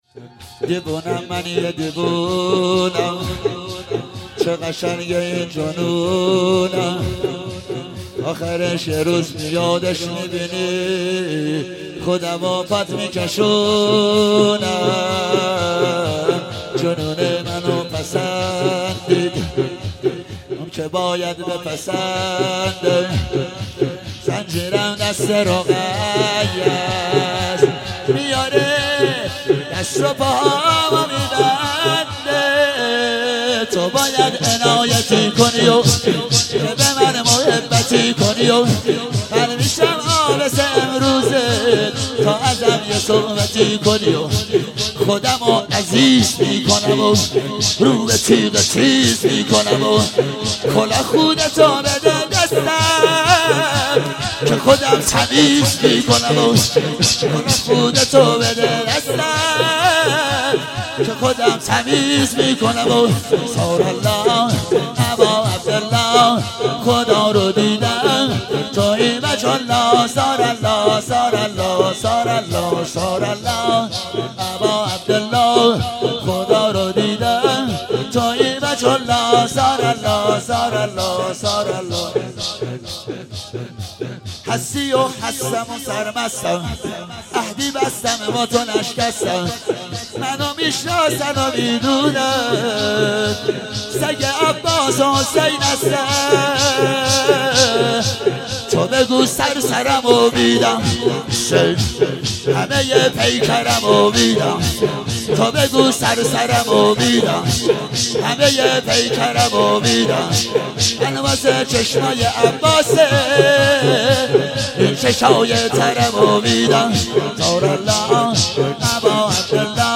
ولادت سرداران کربلا98 - شور - دیوونم من یه دیوونم